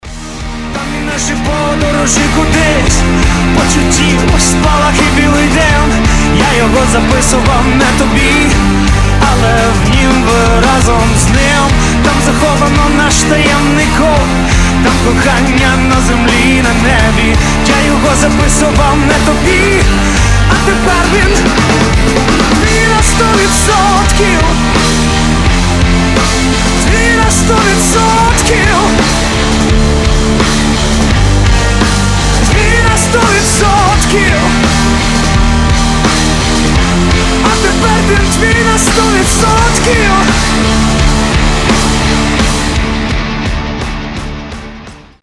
• Качество: 320, Stereo
громкие
украинский рок